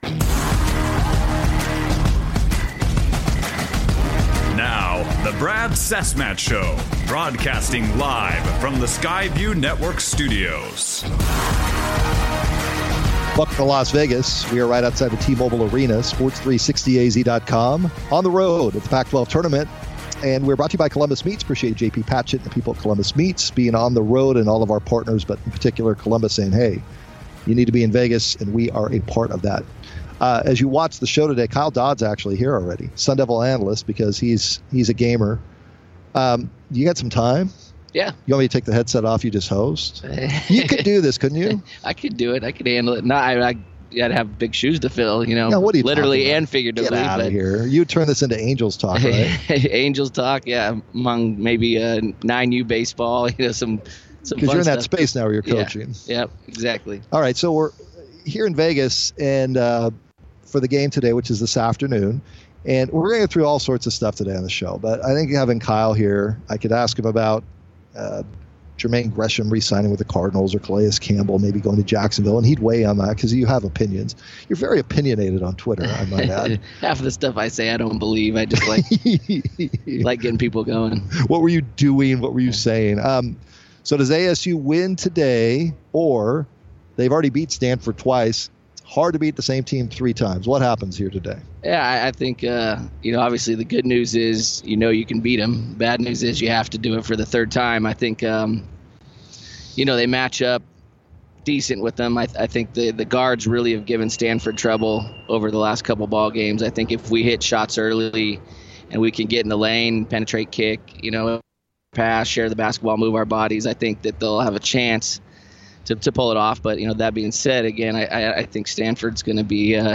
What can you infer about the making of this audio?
LIVE From Pac-12 Tournament In Las Vegas